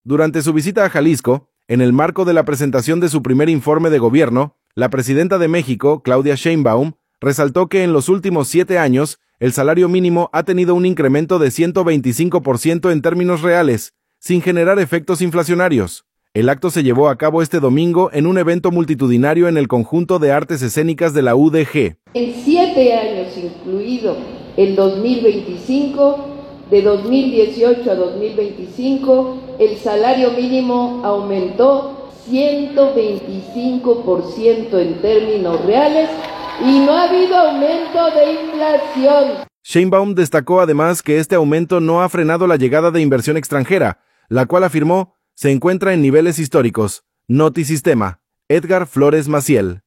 Durante su visita a Jalisco, en el marco de la presentación de su Primer Informe de Gobierno, la presidenta de México, Claudia Sheinbaum, resaltó que en los últimos siete años el salario mínimo ha tenido un incremento de 125 por ciento en términos reales, sin generar efectos inflacionarios. El acto se llevó a cabo este domingo en un evento multitudinario en el Conjunto de Artes Escénicas de la UdeG.